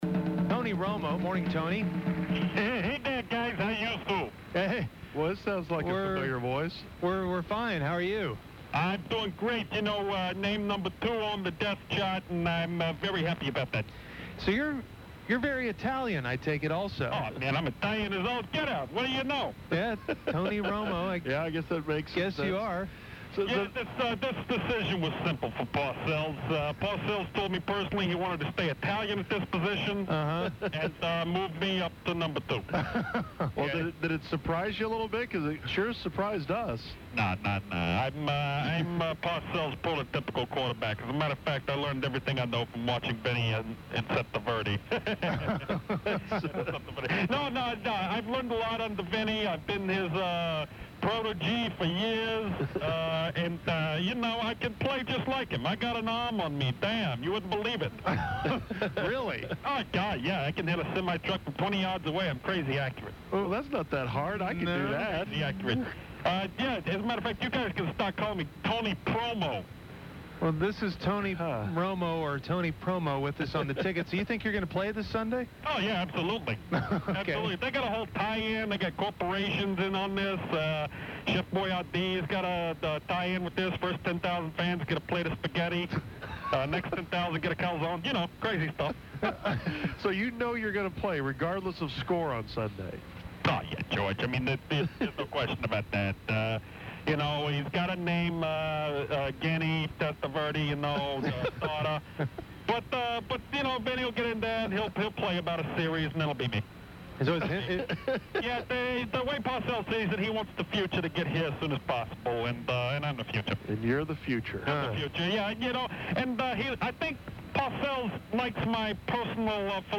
The Musers speak to a very Italian sounding Tony Romo [...]